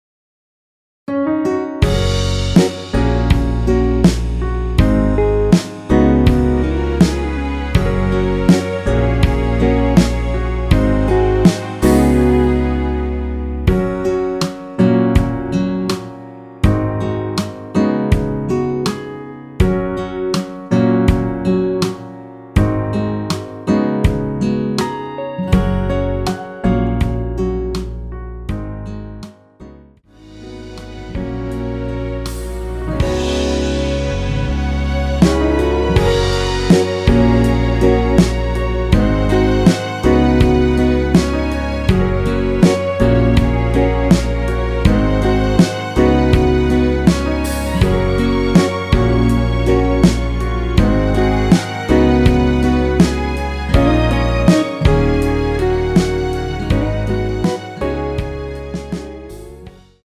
원키에서(+4)올린 MR입니다.
F#
앞부분30초, 뒷부분30초씩 편집해서 올려 드리고 있습니다.
중간에 음이 끈어지고 다시 나오는 이유는